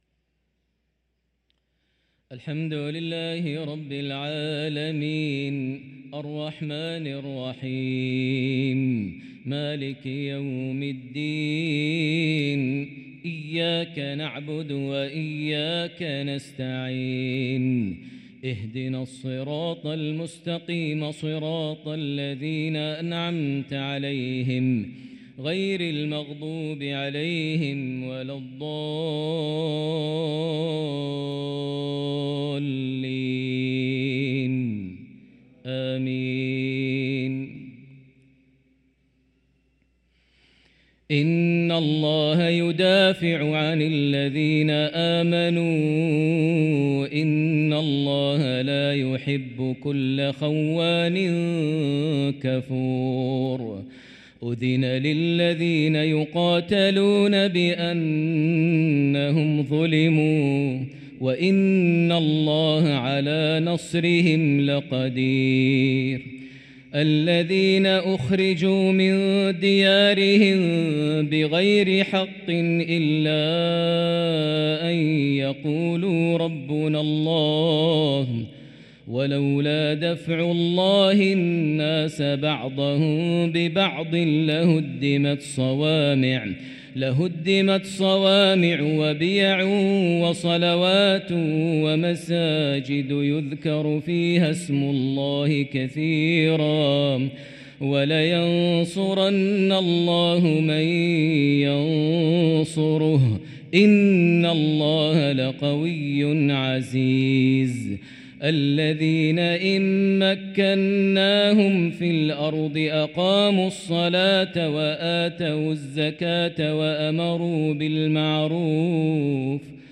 صلاة العشاء للقارئ ماهر المعيقلي 4 جمادي الأول 1445 هـ
تِلَاوَات الْحَرَمَيْن .